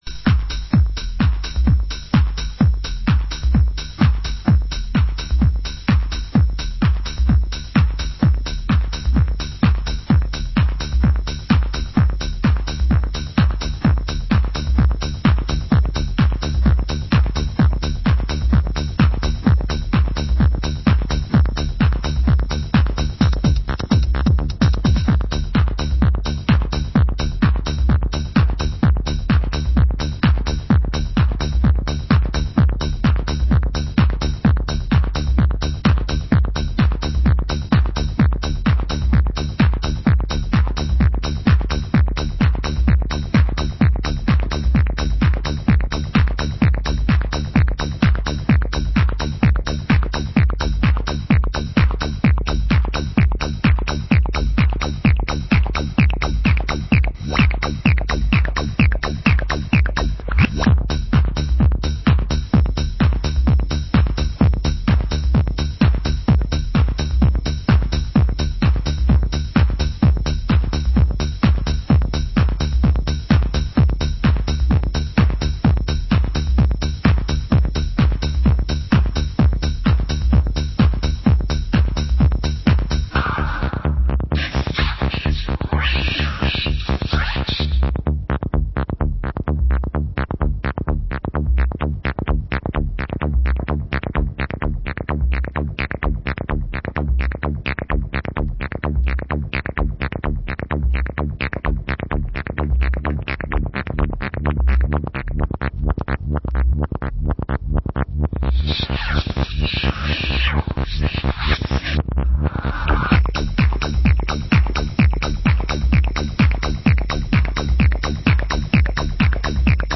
Genre: US Techno